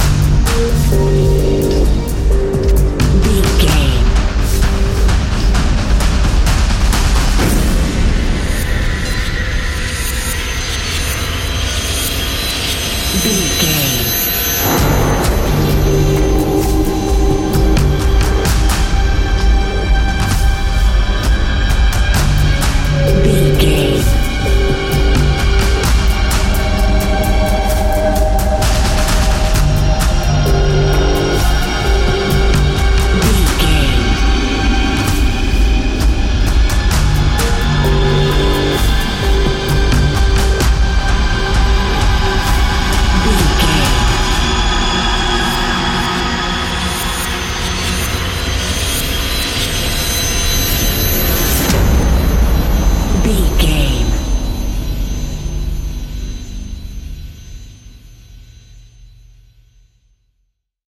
Fast paced
In-crescendo
Ionian/Major
F♯
dark ambient
EBM
experimental
synths